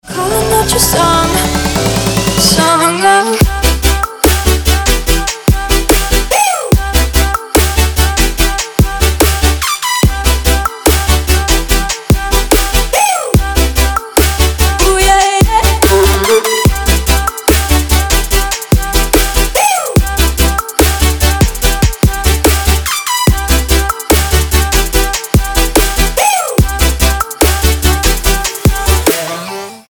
• Качество: 320, Stereo
ритмичные
громкие
женский вокал
заводные
dance
Electronic
electro